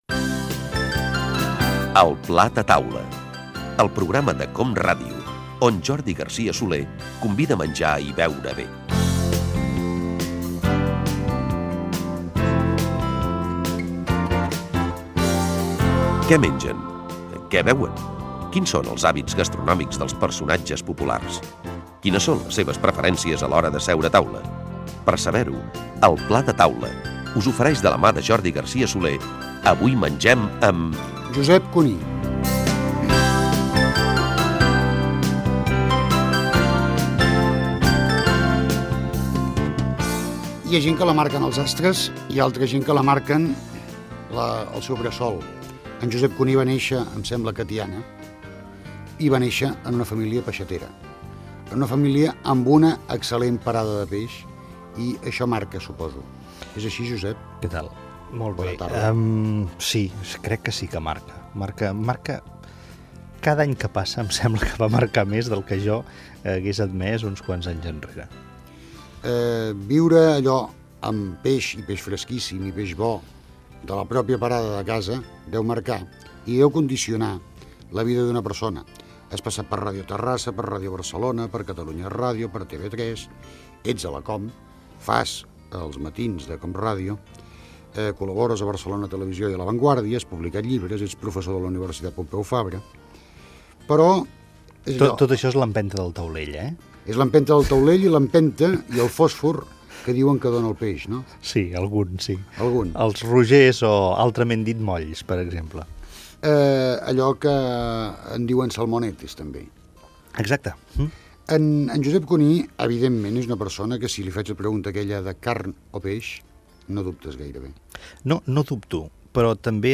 Careta del programa, presentació i fragment d'una entrevista al periodista Josep Cuní. S'hi parla de la seva família i de la seva vida professional
FM